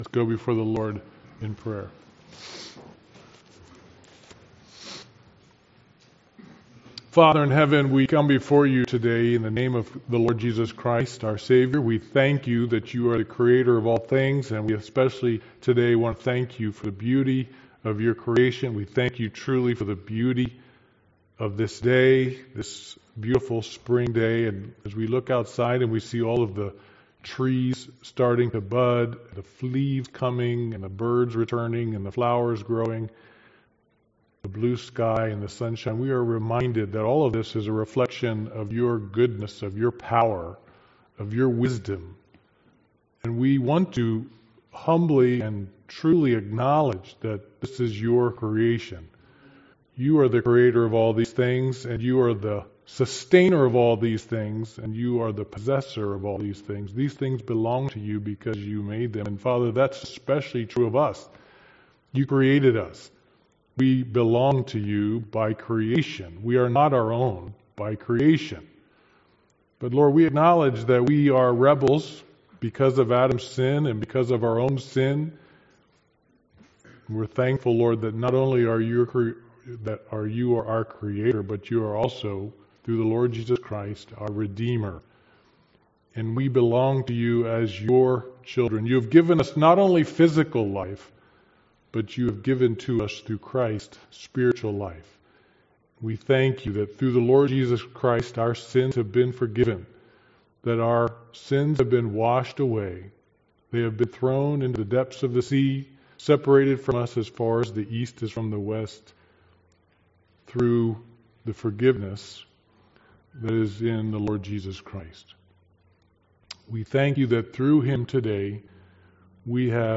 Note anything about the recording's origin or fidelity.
Passage: John 17 Service Type: Sunday Morning Worship